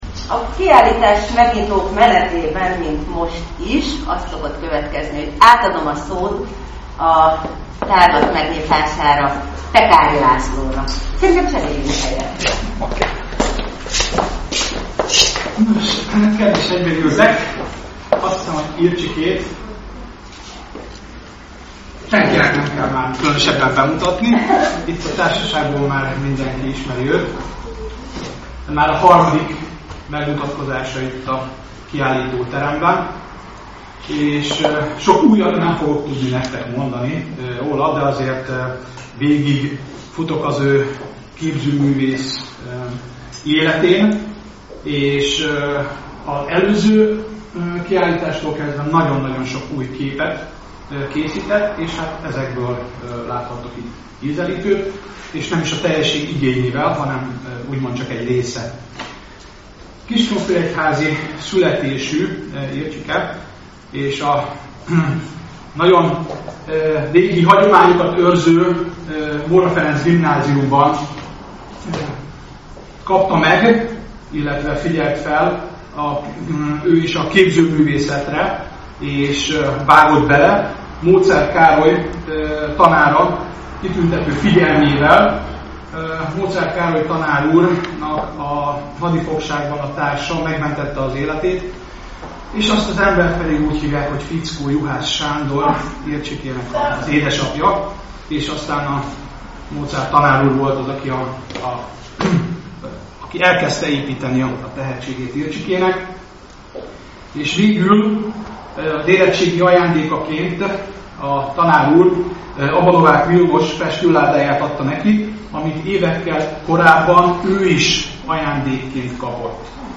Az első találkozás a publikummal minden esetben az ünnepélyes megnyitó.